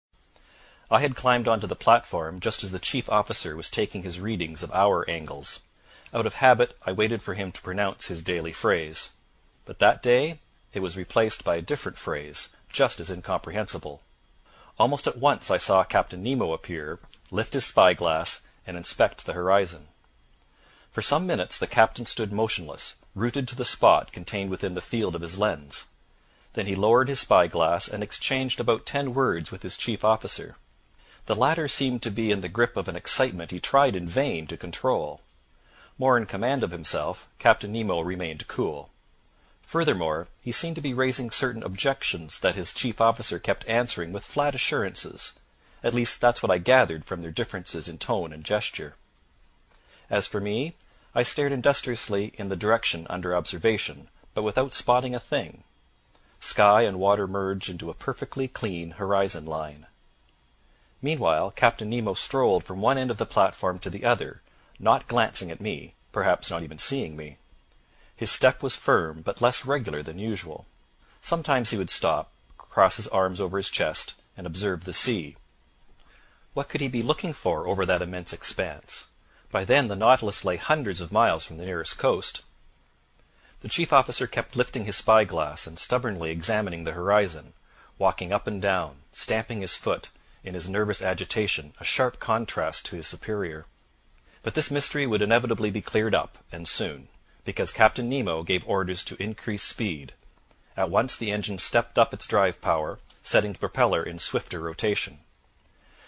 英语听书《海底两万里》第301期 第22章 强逼睡眠(6) 听力文件下载—在线英语听力室